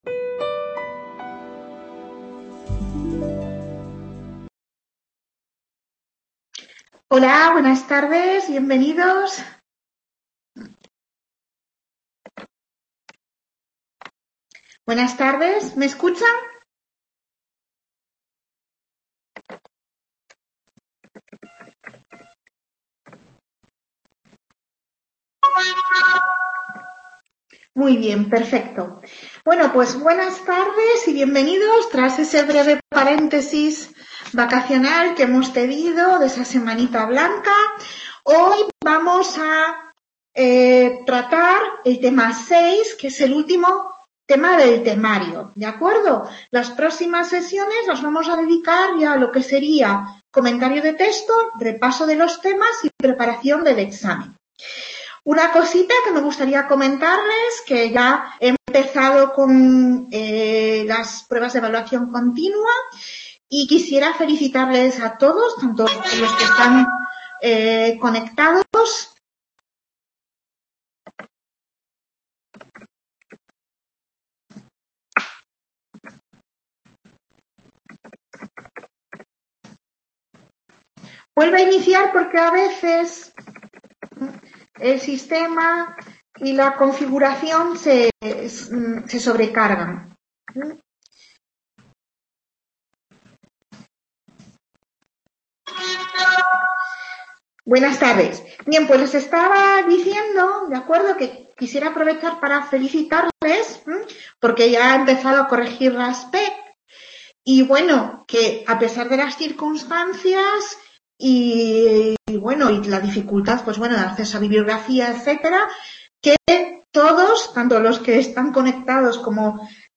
Tutoría dedicada al tema 6 de la asignatura La construcción historiográfica del arte